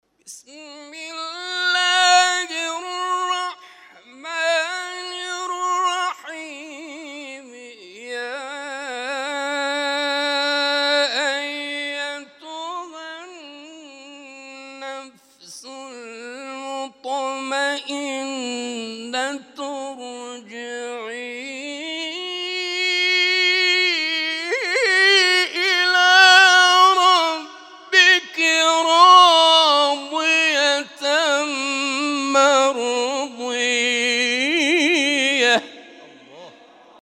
تلاوت‌های محفل هفتگی انس با قرآن آستان عبدالعظیم(ع) + دانلود
محفل هفتگی انس با قرآن در آستان عبدالعظیم(ع) + صوت